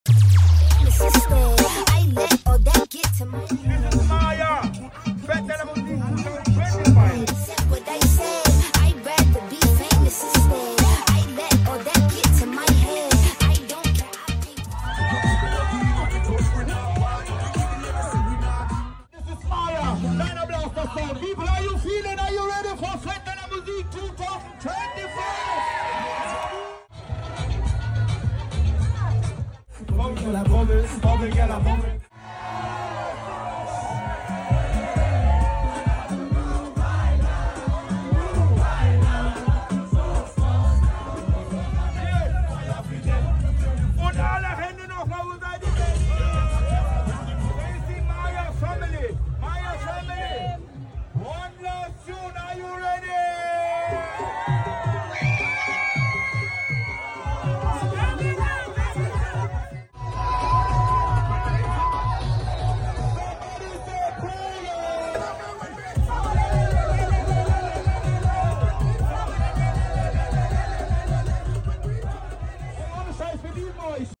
Es war laut.